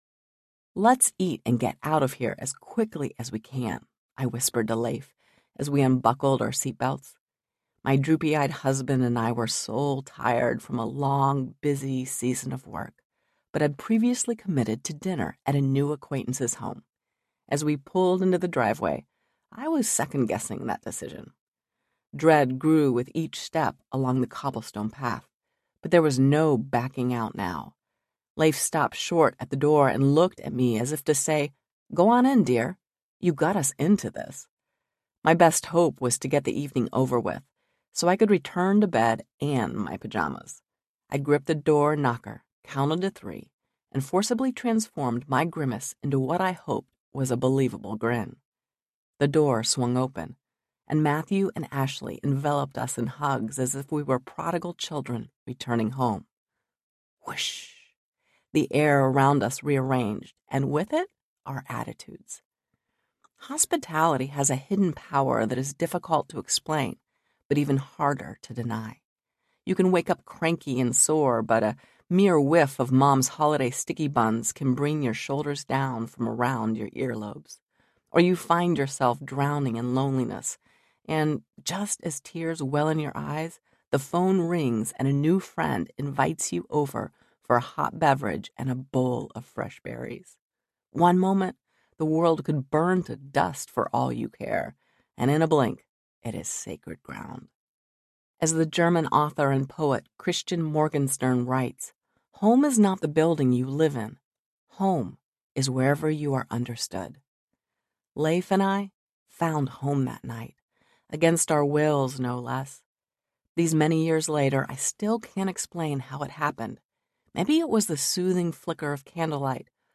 Taste and See Audiobook